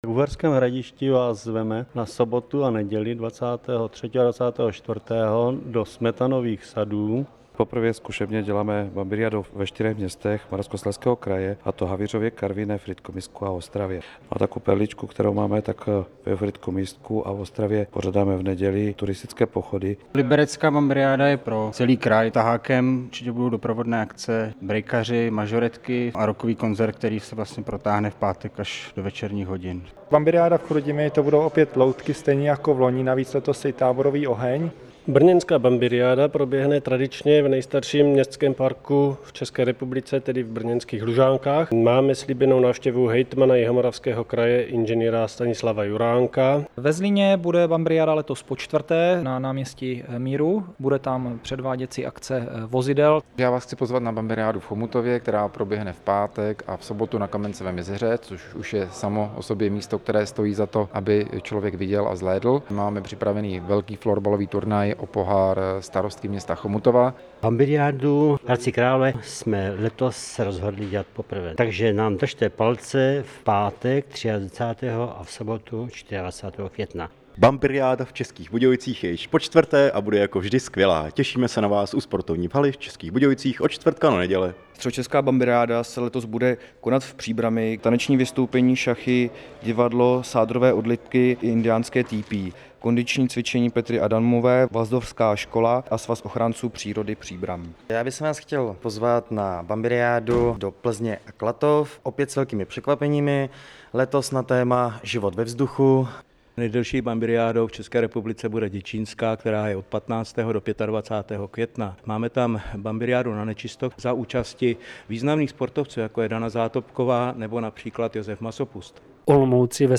zvukové nahrávky z Bambiriády a o Bambiriádě
Upoutávky na Bambiriádu 2003